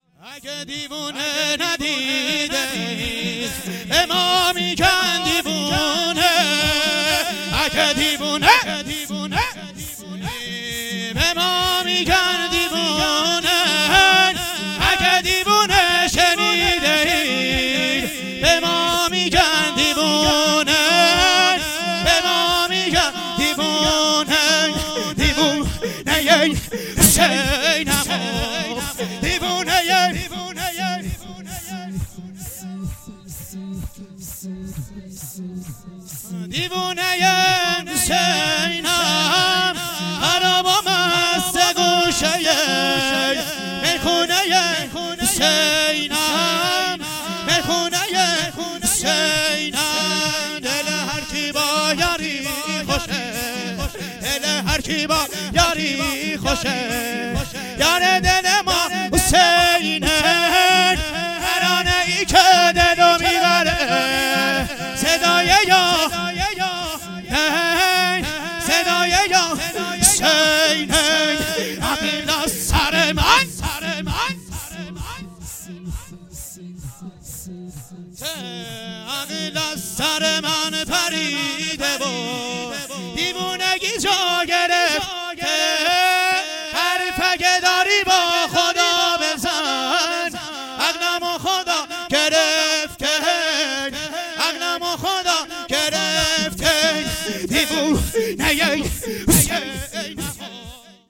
جشن باشکوه ولادت امام رضا (ع)